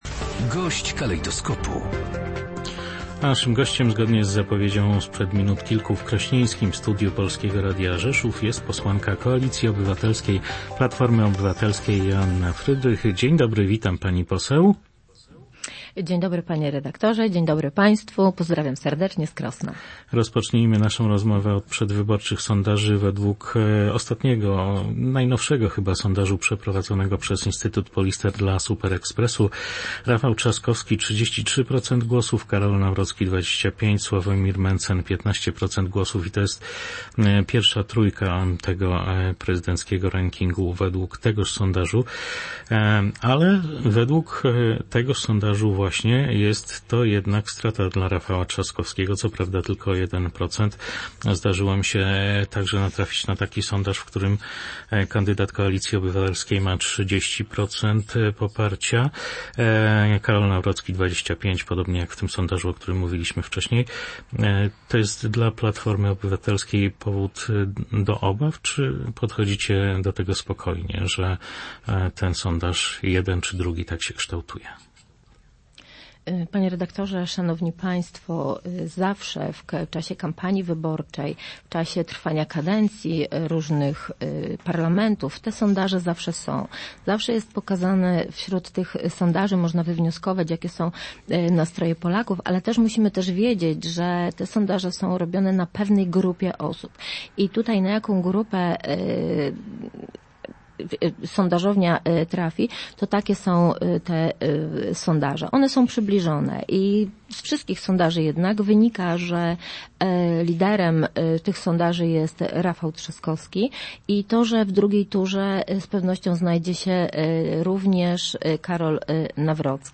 Gość dnia • W Ukrainie trwa ekshumacja ofiar zbrodni wołyńskiej.
– powiedziała na naszej antenie Joanna Frydrych, posłanka Koalicji Obywatelskiej- Platformy Obywatelskiej.